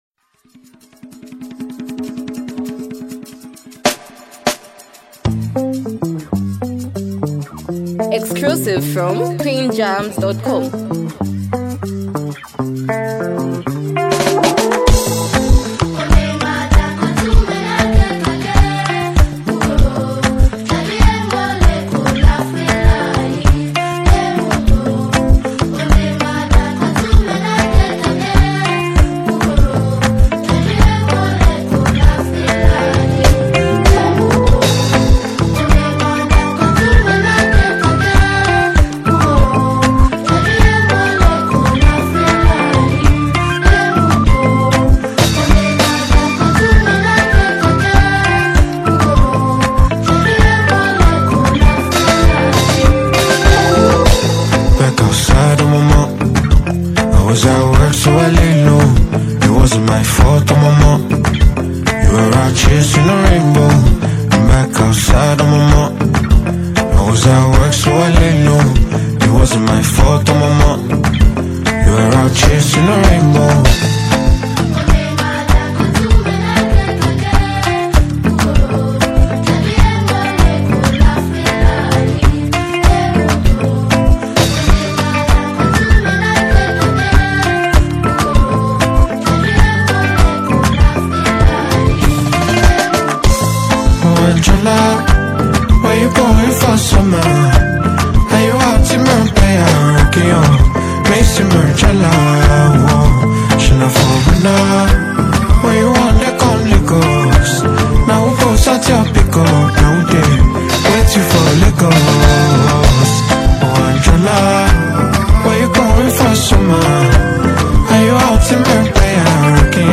a smooth fusion of Afrobeats and contemporary R&B
With its smooth production
and captivating vocals